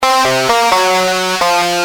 Lead_b6.wav